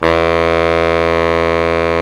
Index of /90_sSampleCDs/Roland LCDP06 Brass Sections/BRS_Pop Section/BRS_Pop Section1
SAX B.SAX 10.wav